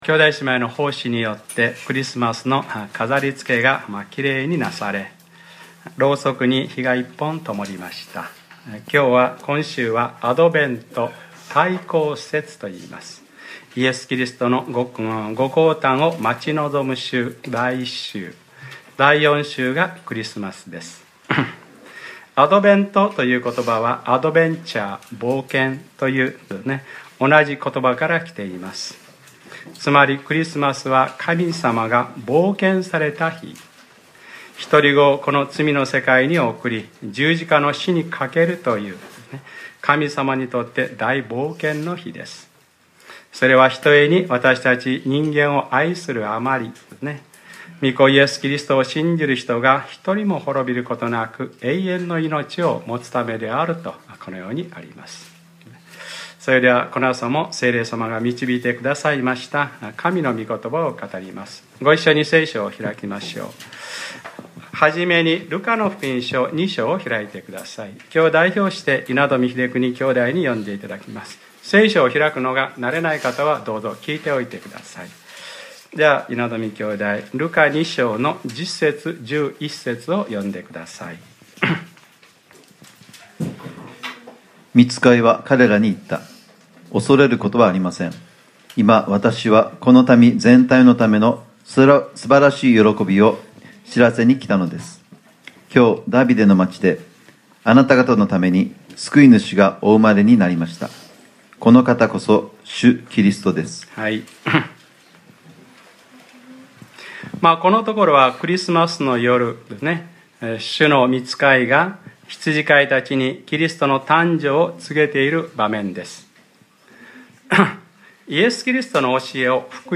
2014年11月30日（日）礼拝説教 『黙示録ｰ３６：もはやのろわれるものは何もない』